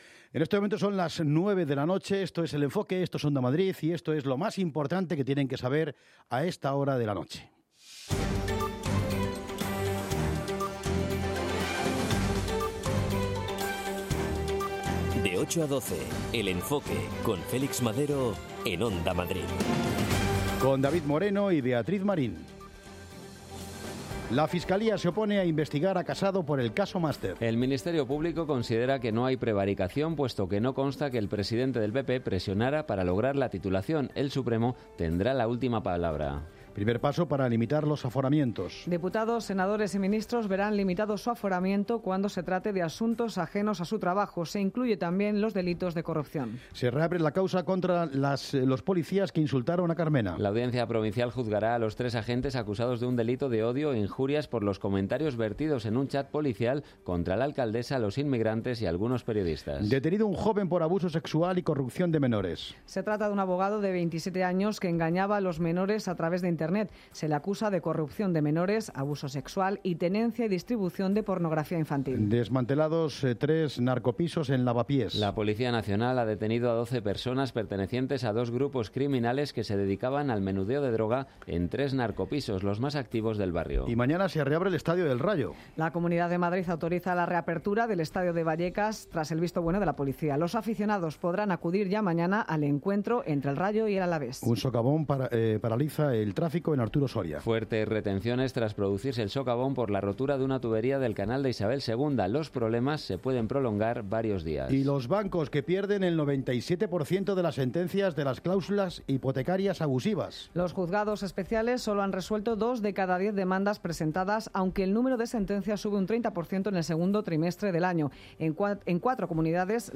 Entrevistas a los protagonistas, reportajes, tertulias… todo tiene cabida en este espacio deportivo